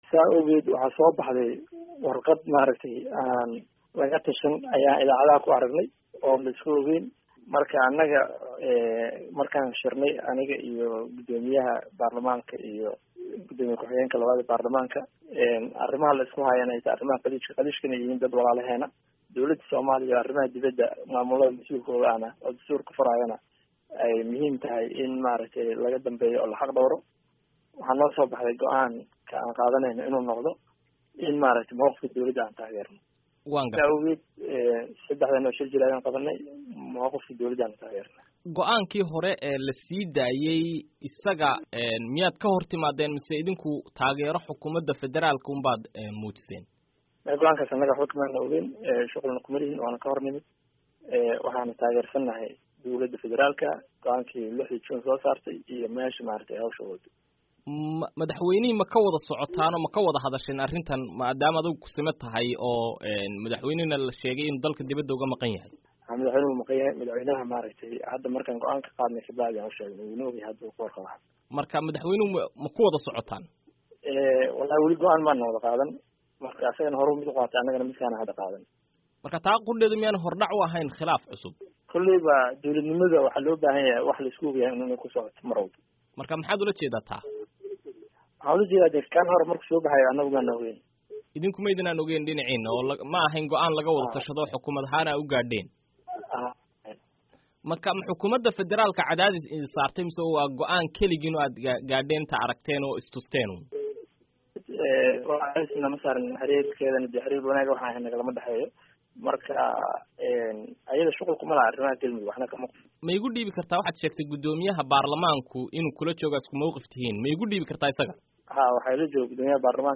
Waraysiyada Galmudug